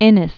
(ĭnĭs), George 1825-1894.